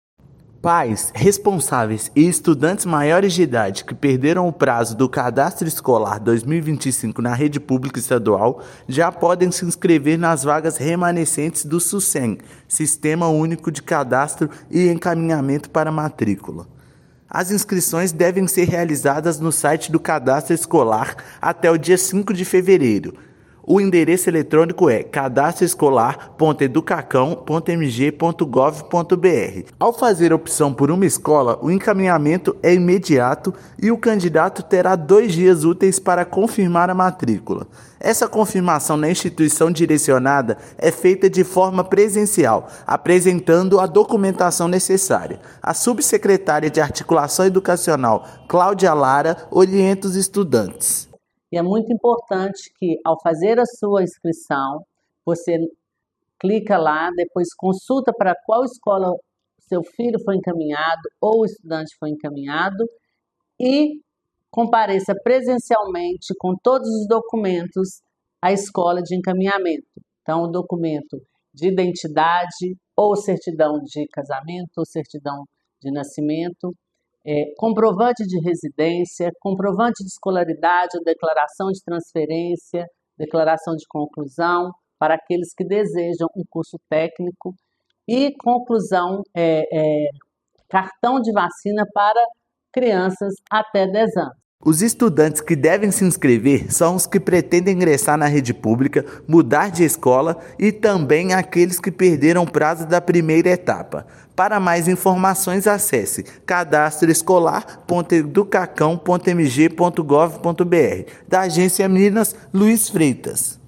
Inscrições podem ser realizadas pelo Sucem até 5/2; interessados têm até dois dias úteis para confirmar matrícula presencialmente na unidade escolar. Ouça matéria de rádio.